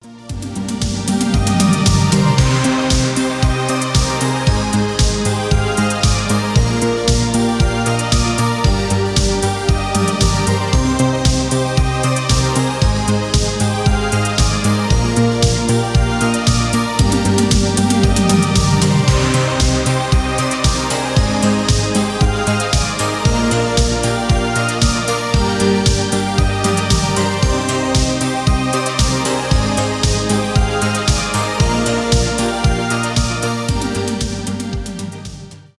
танцевальные
без слов
synthwave